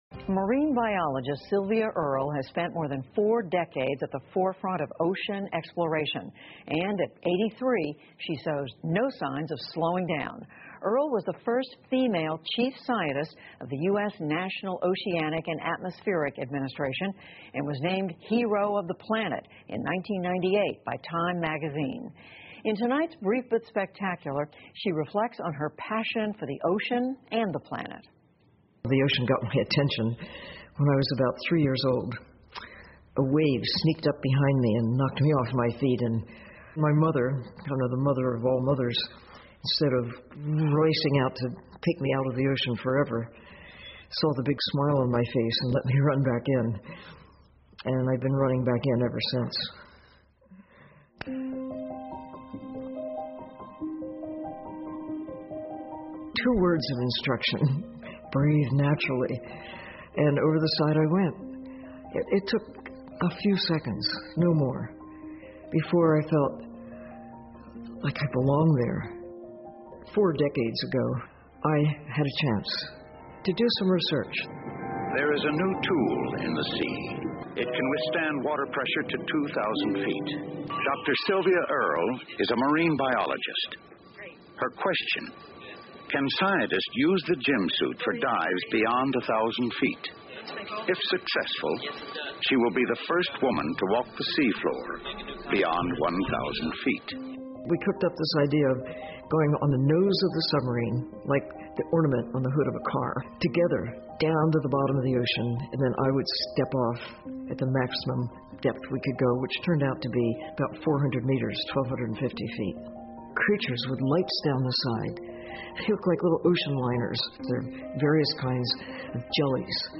PBS高端访谈:激发人们对海洋的热爱 听力文件下载—在线英语听力室